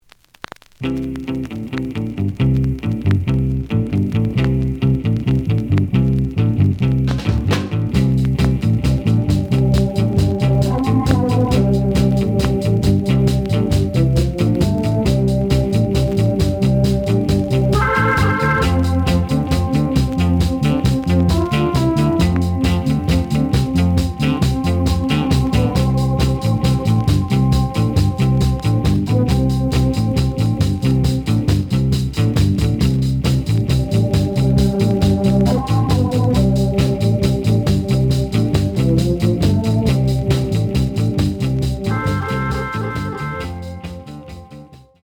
試聴は実際のレコードから録音しています。
The audio sample is recorded from the actual item.
●Genre: Soul, 60's Soul